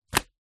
Звуки игральных карт
положил козырь на стол